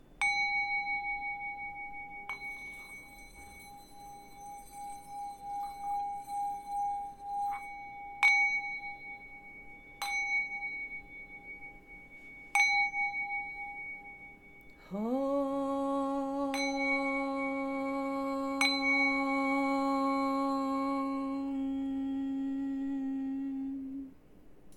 Además, al ser del tipo condensador le suma absoluta claridad. En este caso probamos con los cuencos que tienen sonidos muy agudos.
MAONO-AU-PM422-Prueba-de-Voz-1.mp3